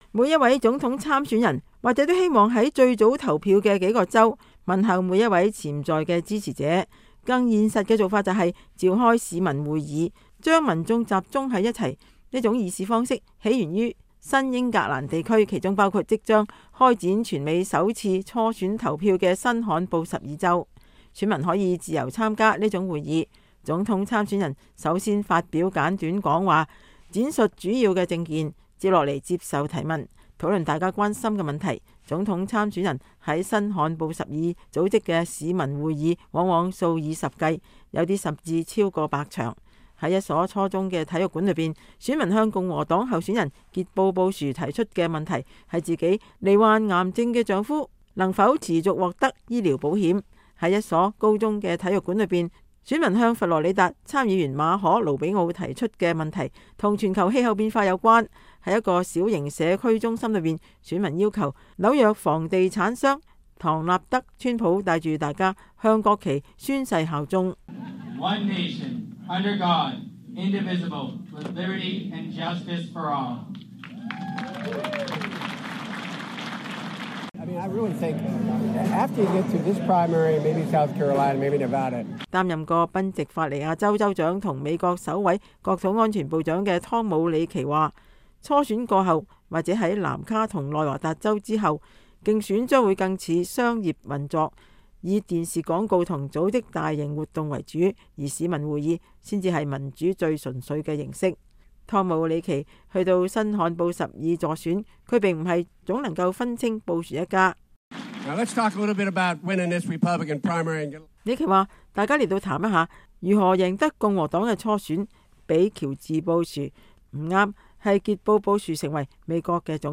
新罕布什爾州曼切斯特 —